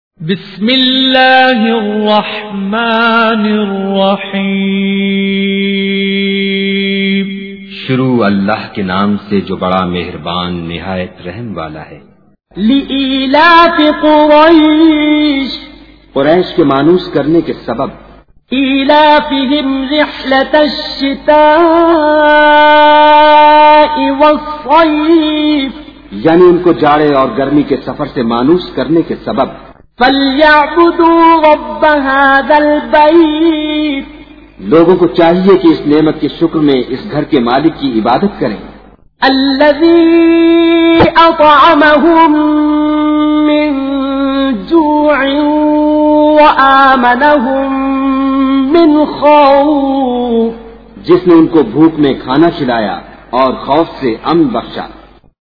تلاوت بااردو ترجمہ